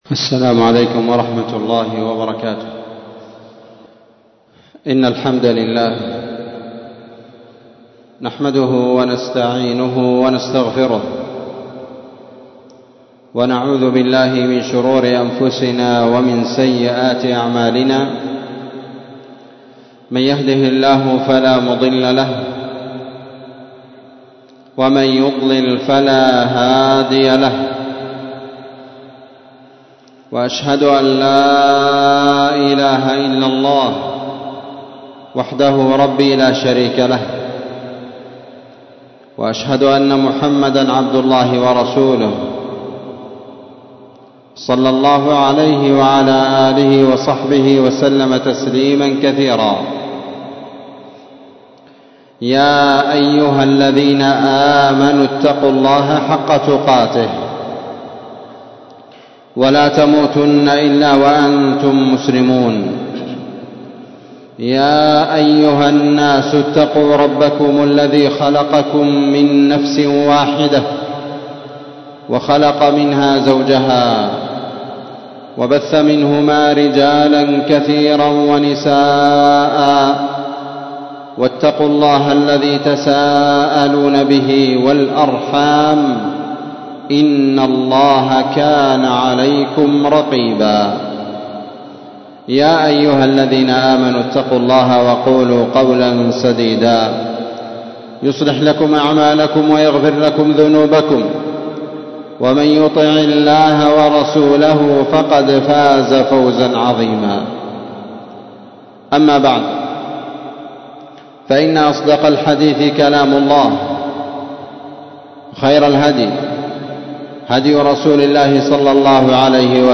خطبة جمعة
مسجد المجاهد - تعز